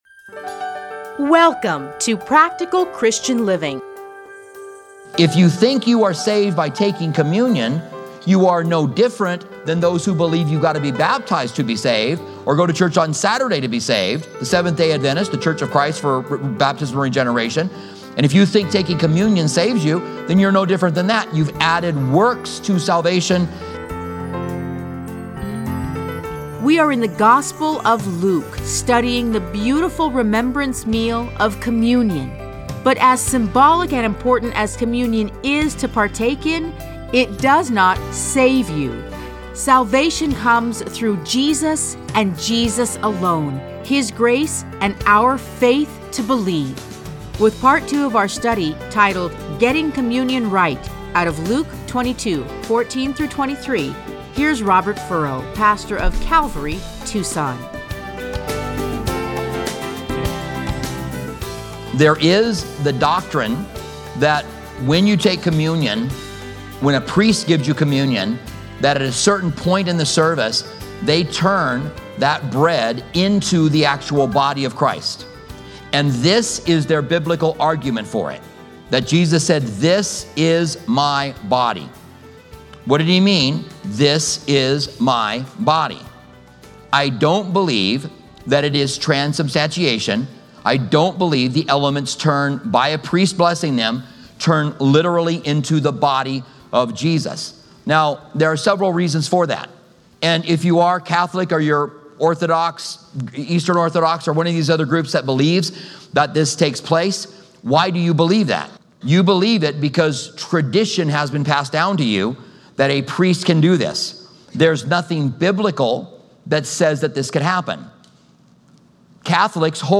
Listen to a teaching from Luke 22:14-23.